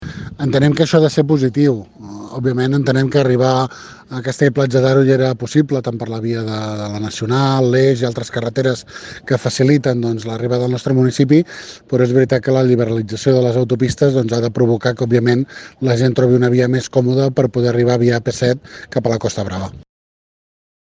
Per una altra banda, Maurici Jiménez, l’alcalde de Castell-Platja d’Aro (un dels municipis més turístics del Baix Empordà) explica que, òbviament, ja es podia arribar al municipi sense haver de pagar, però apunta que l’aixecament dels peatges farà que hi hagi una via més còmoda per arribar a la Costa Brava.